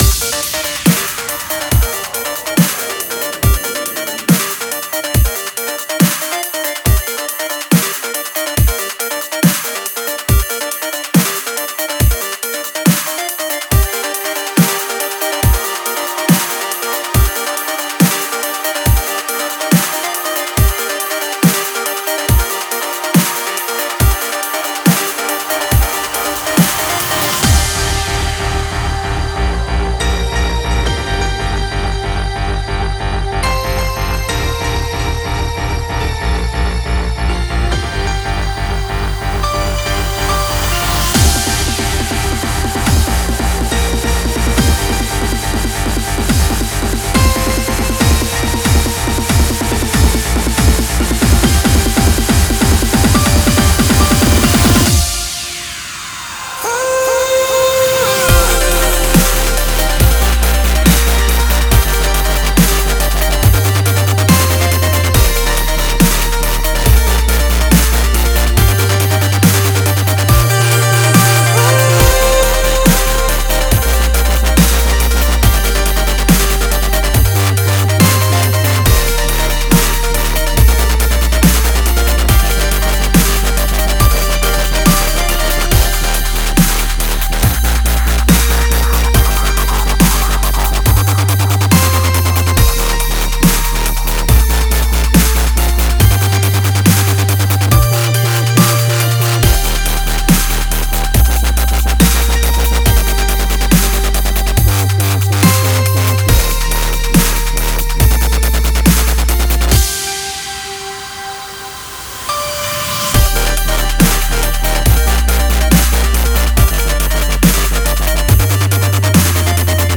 Dub step [31]